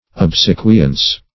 Obsequience \Ob*se"qui*ence\, n.